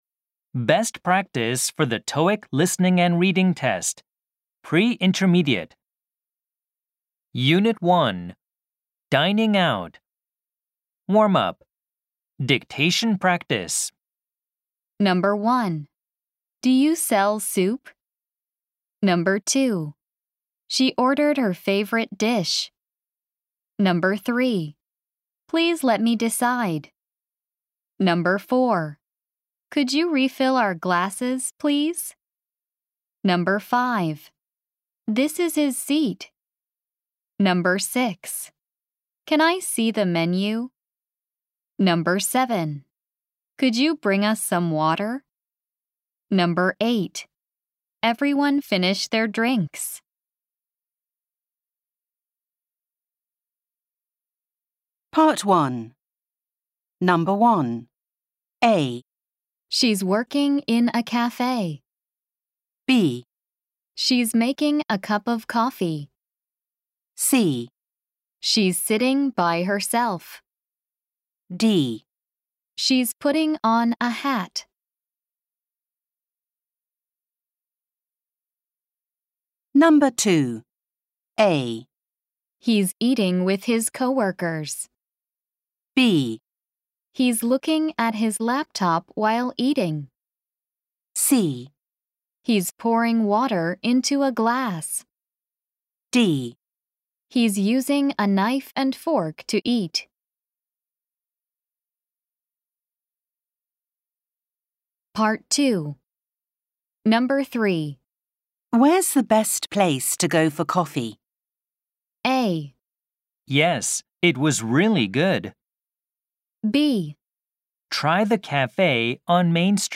吹き込み Amer E ／ Brit E